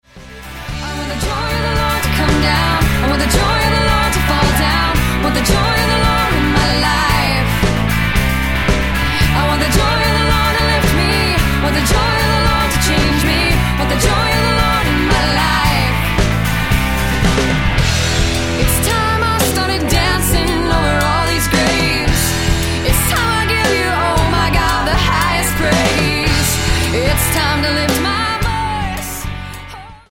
worship album
a wide range of expression from joyous rapture